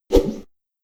Melee Weapon Air Swing 7.wav